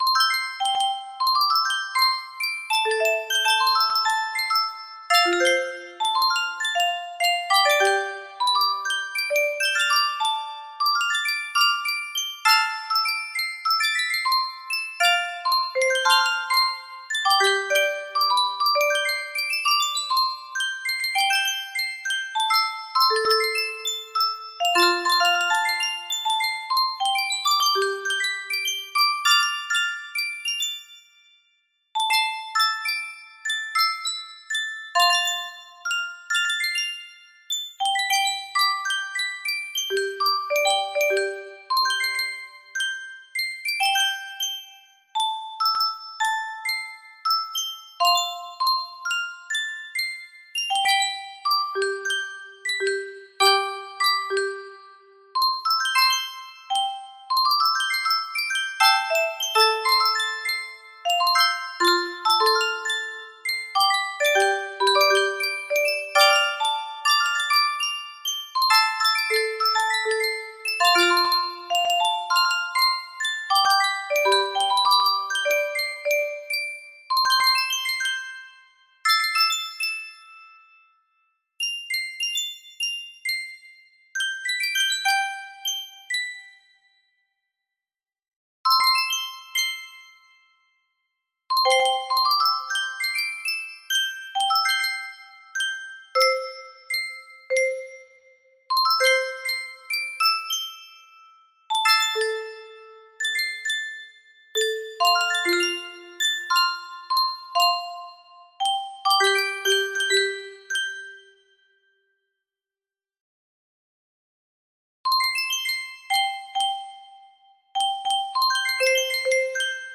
Threads Of Gold 4 music box melody
Full range 60